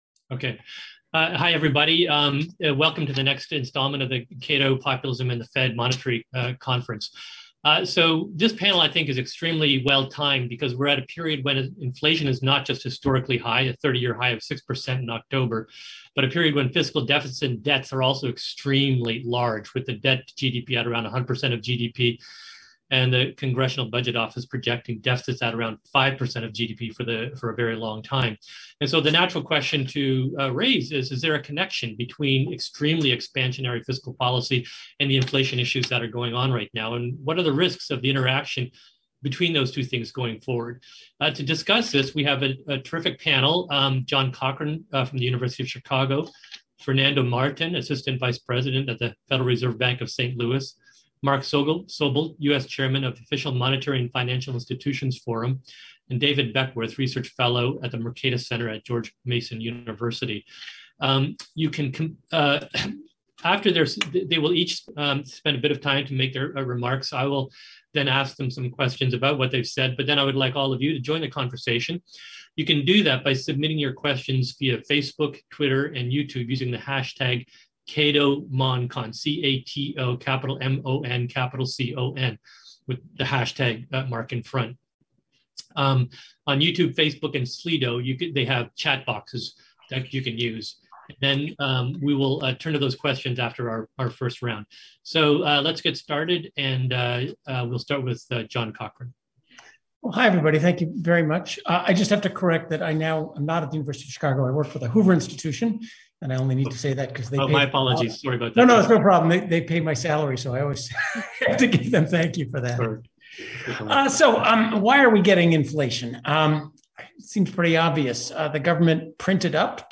Cato’s 39th Annual Monetary Conference—Populism and the Future of the Fed—will bring together leading scholars and policymakers to explore the risks that populism poses to the conduct of monetary policy.